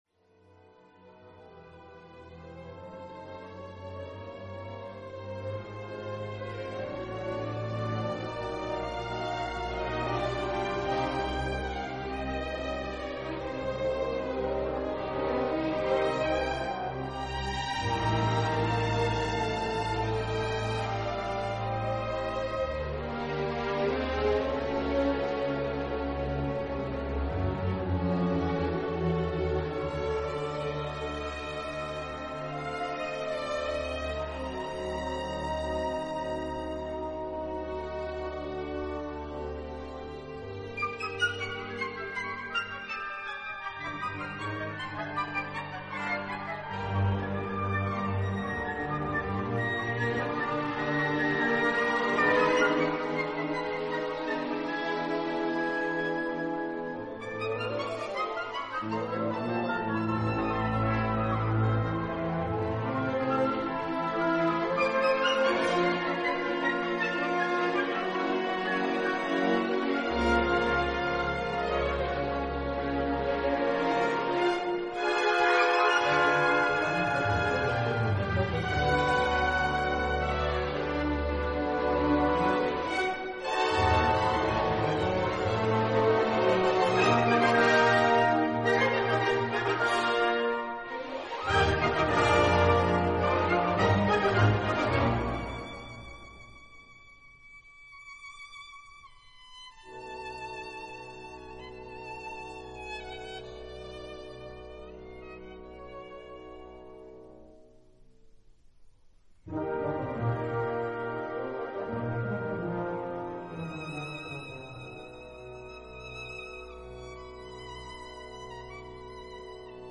Then we close with Richard Strauss’ “monster” piece Ein Heldenleben in all its powerfully inventive and romantic glory.